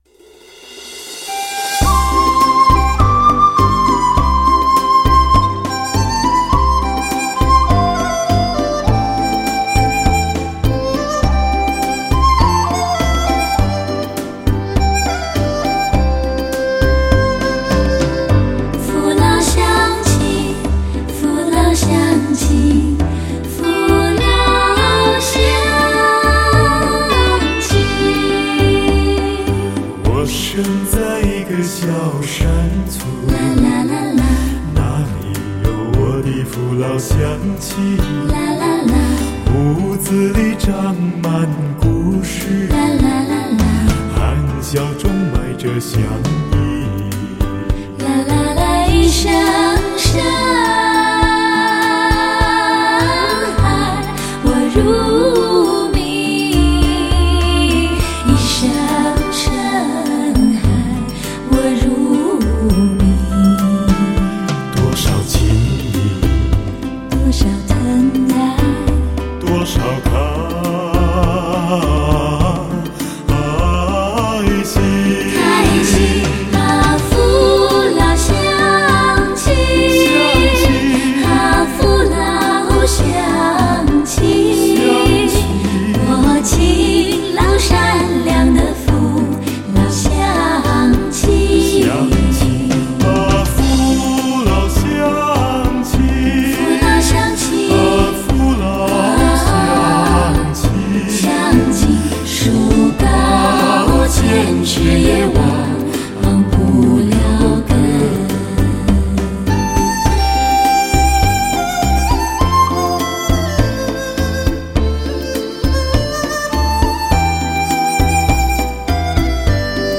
全新演唱，并非原唱。